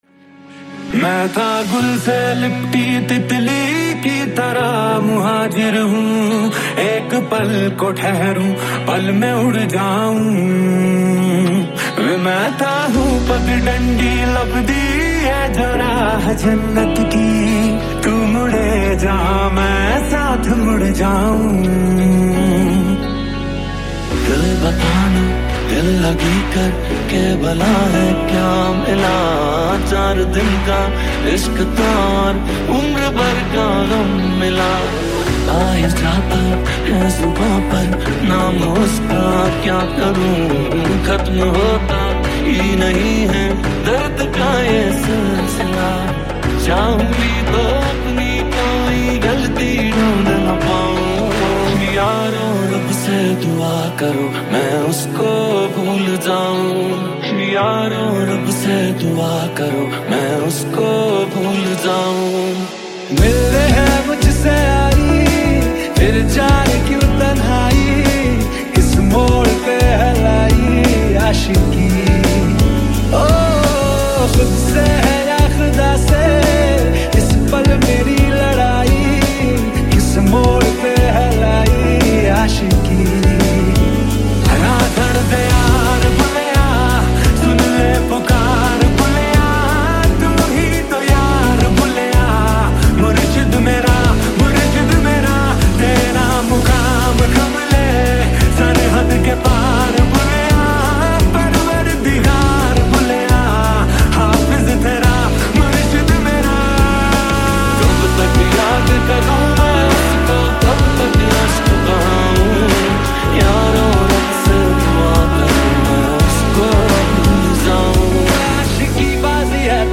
High quality Sri Lankan remix MP3 (4.8).
remix